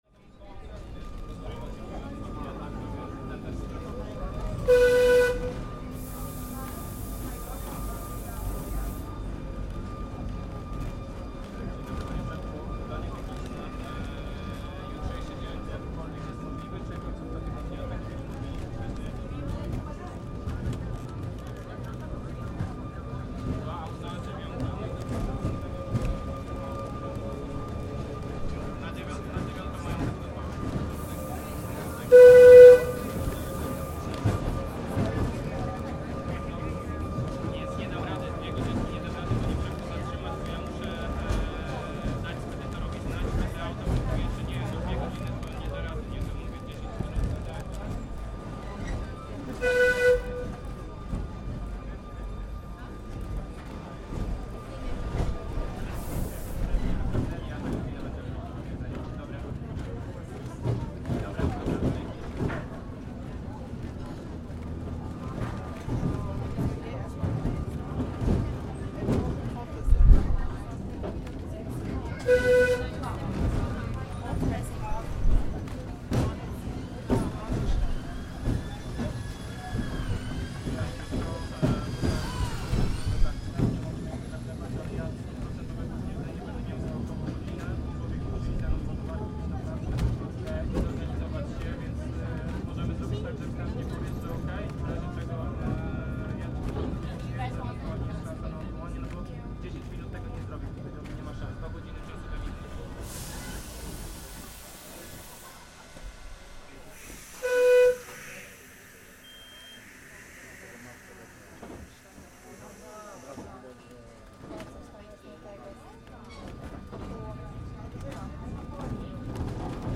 A traditional wooden tram ride through the centre of Soller in Mallorca, with distinctive toots from the horn warning pedestrian tourists to keep off the tracks and out of the way of the tram as it clatters through the historical old town.